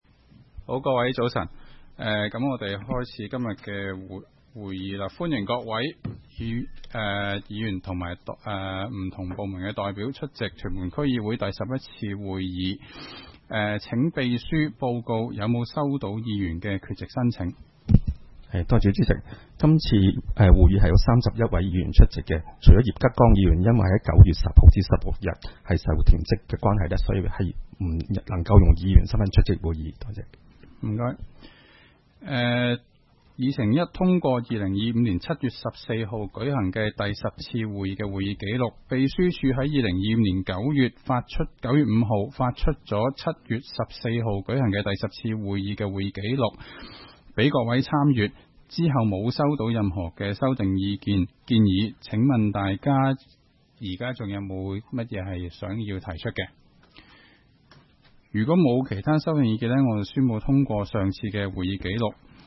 区议会大会的录音记录
屯门区议会会议室